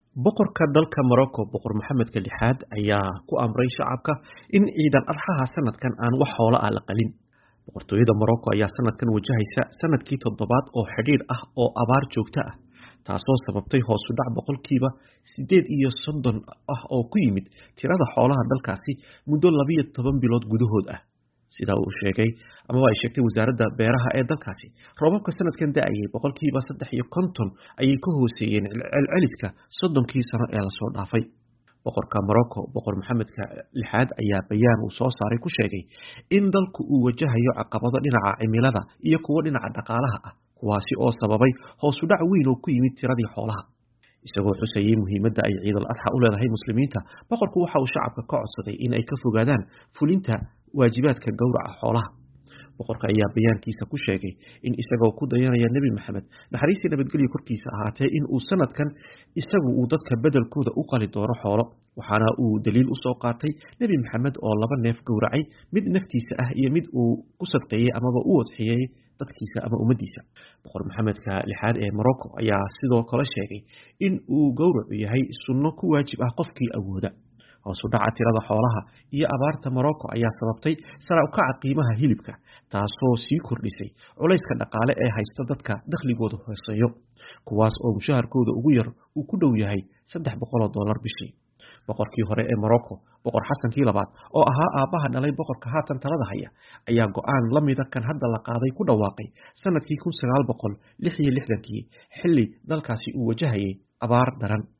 Warbixin ay qortay wakaaladda wararka ee AFP